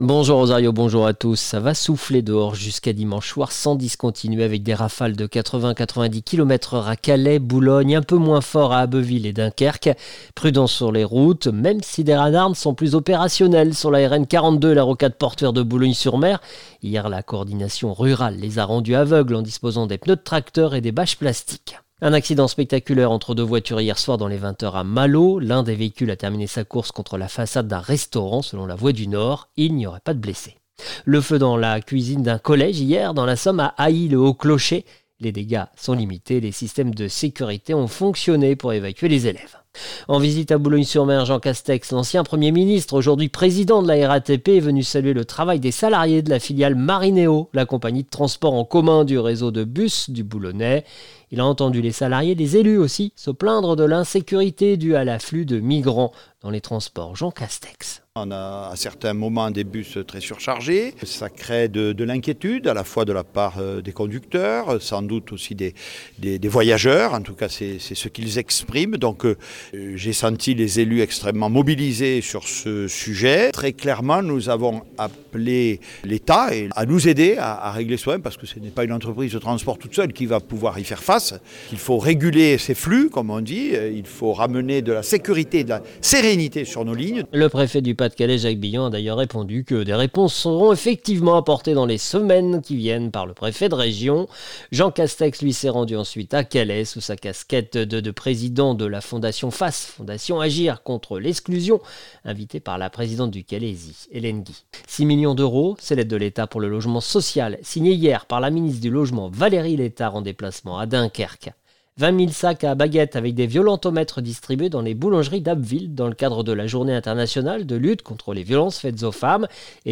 Le journal du samedi 23 novembre 2024